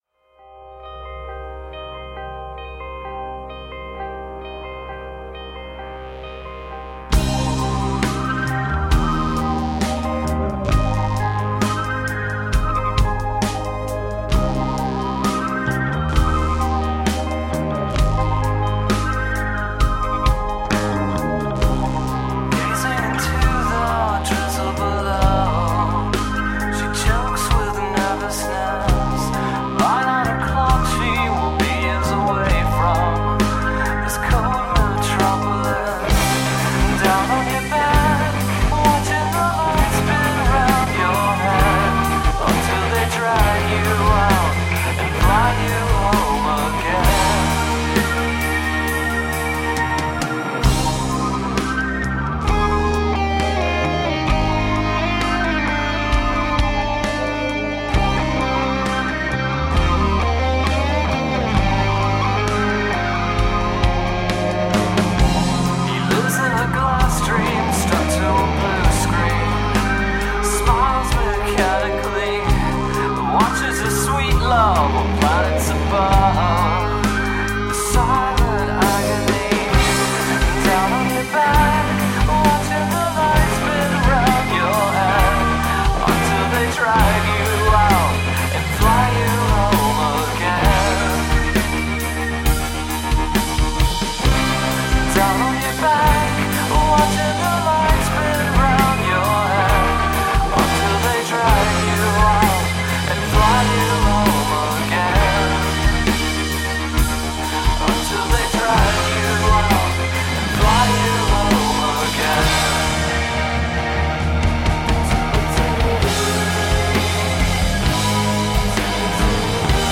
Up-beat rock and new wave for the 21st century.
Tagged as: Alt Rock, Pop